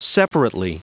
Prononciation du mot separately en anglais (fichier audio)
Prononciation du mot : separately